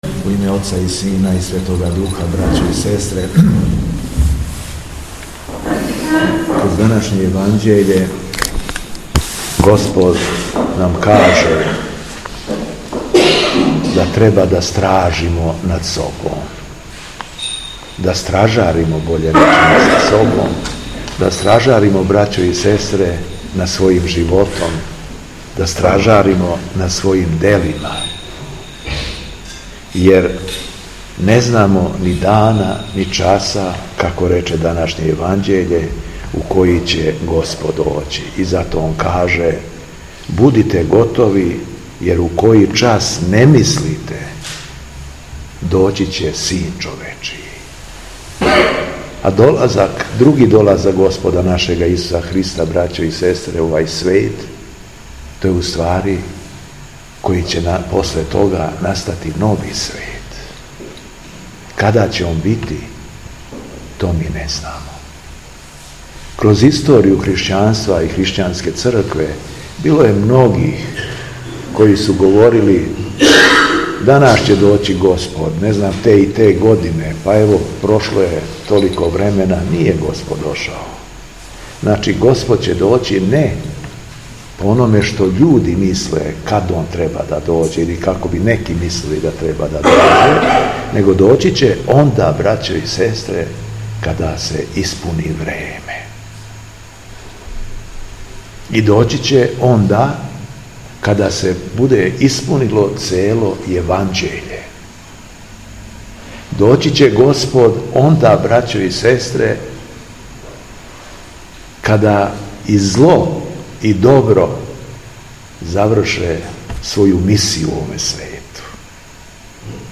Беседа Његовог Преосвештенства Епископа шумадијског г. Јована
Након прочитаног Јеванђеља Преосвећени Владика се обратио верном народу: